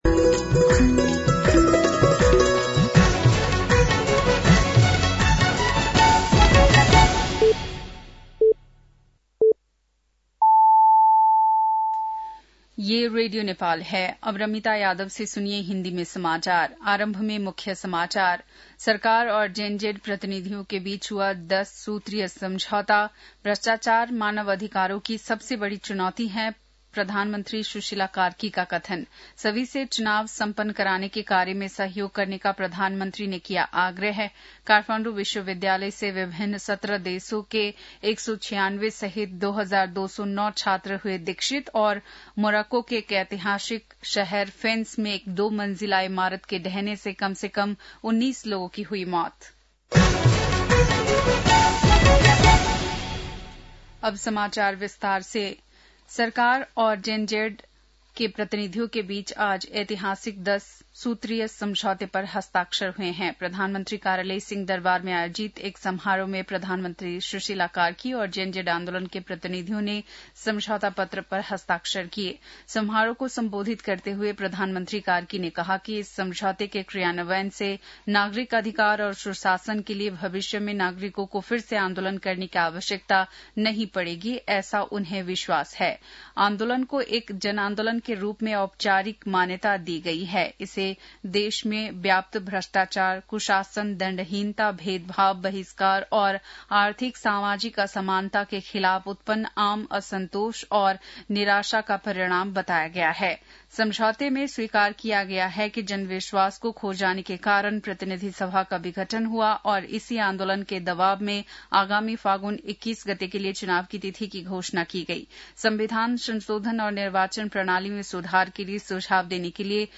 बेलुकी १० बजेको हिन्दी समाचार : २४ मंसिर , २०८२
10-pm-hindi-news-8-24.mp3